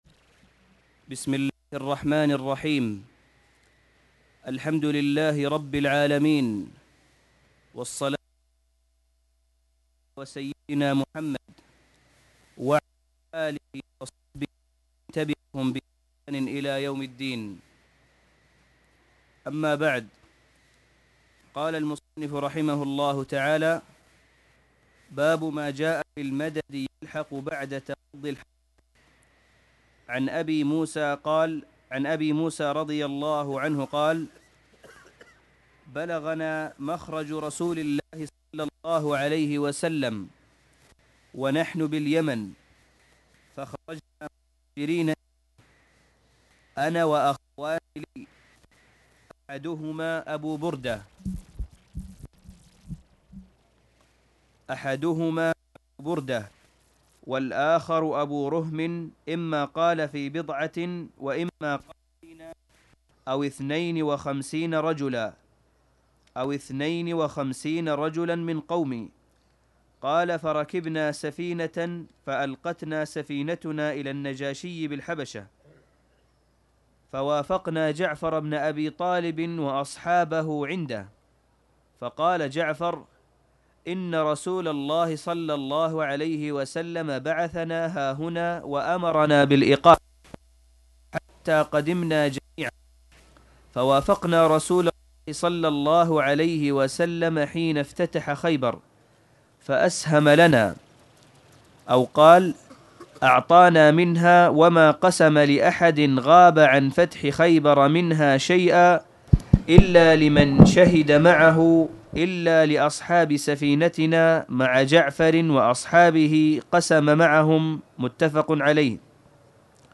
تاريخ النشر ١٥ صفر ١٤٣٨ هـ المكان: المسجد الحرام الشيخ: معالي الشيخ أ.د. صالح بن عبدالله بن حميد معالي الشيخ أ.د. صالح بن عبدالله بن حميد باب ما جاء في المدد يلحق بعد قضاء الحرب